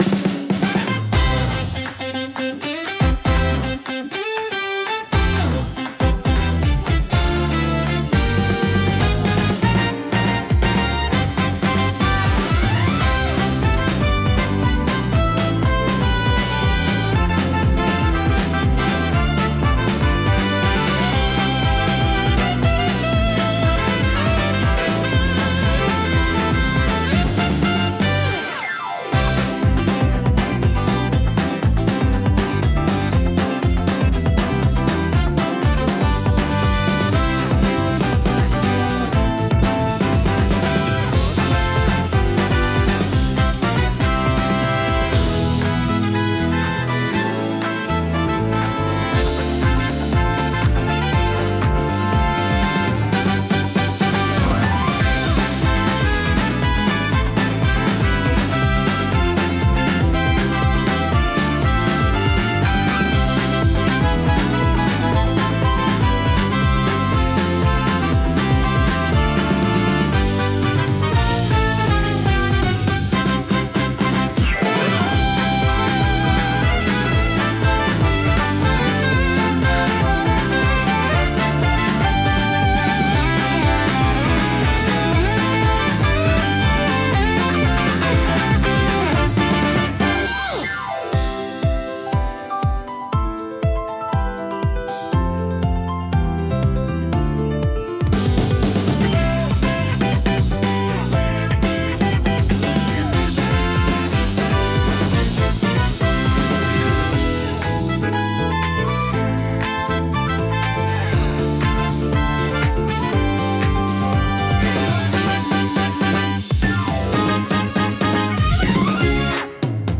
賑やかでフレンドリーな環境を求めるシーンにぴったりのジャンルです。